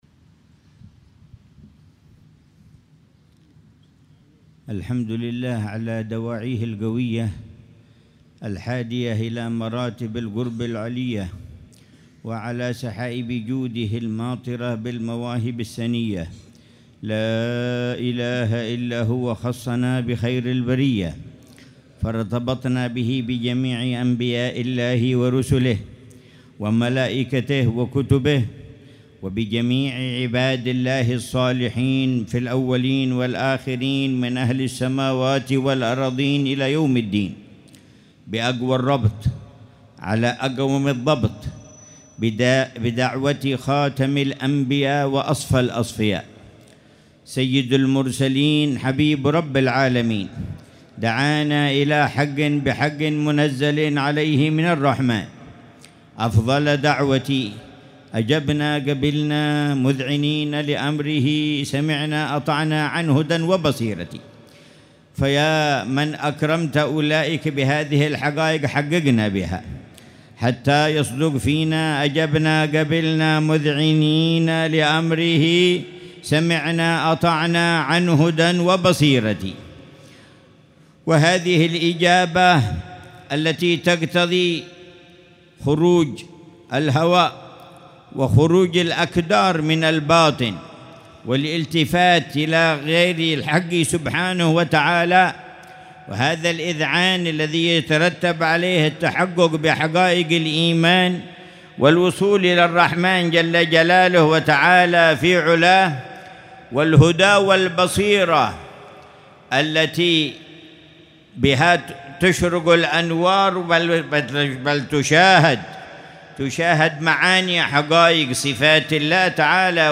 مذاكرة